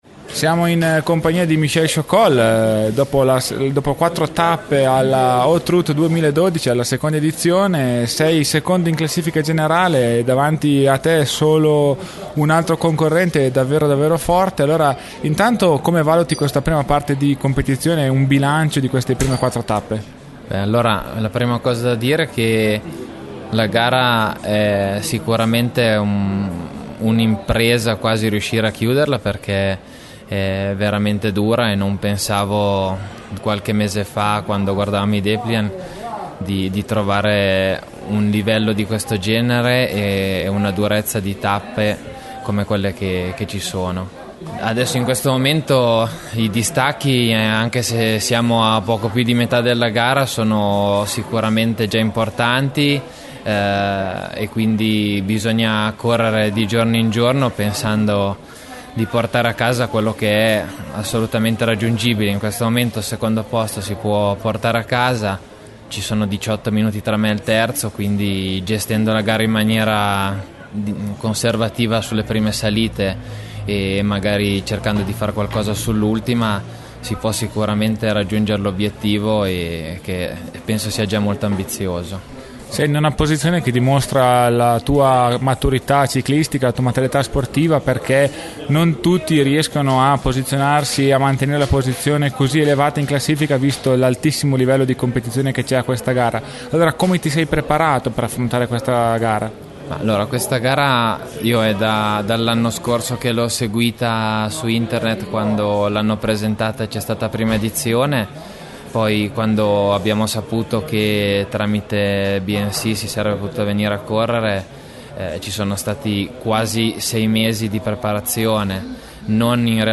Audio intervista